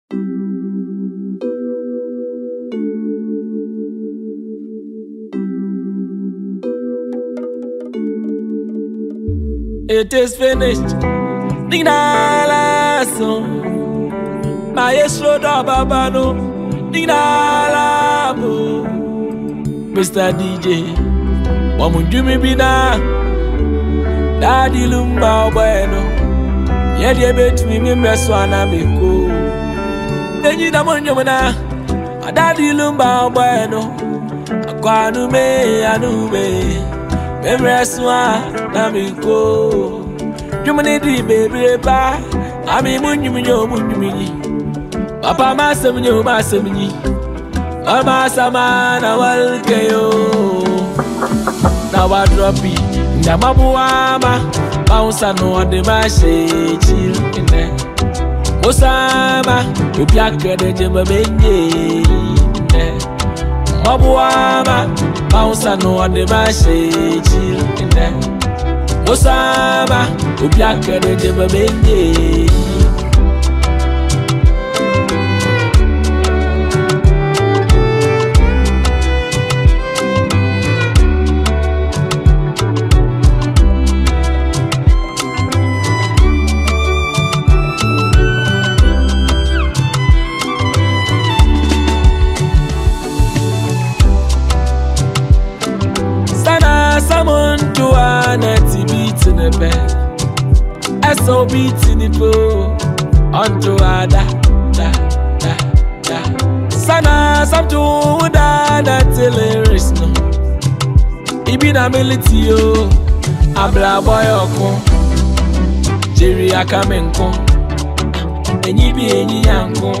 Ghana Music
Multiple award-winning Ghanaian singer and songwriter